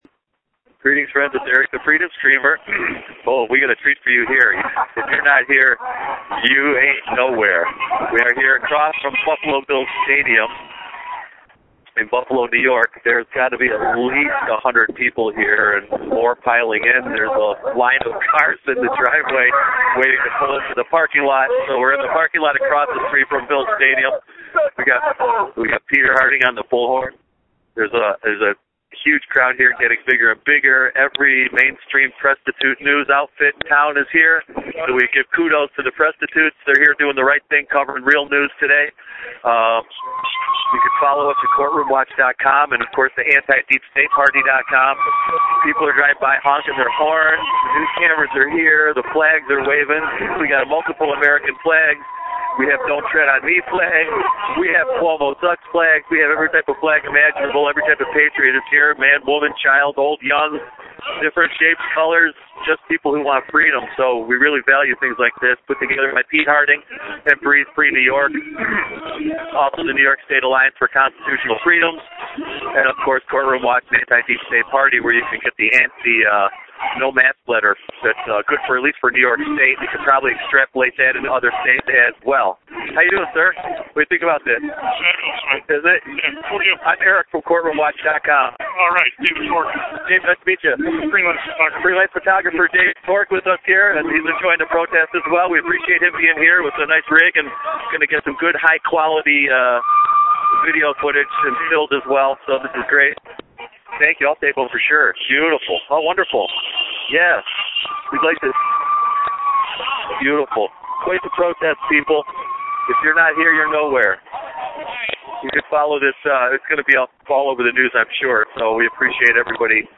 we play the audio from a meeting of local buisness owners In Buffalo who stand their ground against the uninvited health dept and sheriff deputies .